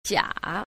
9. 假 – jiǎ – giả, giá
jia.mp3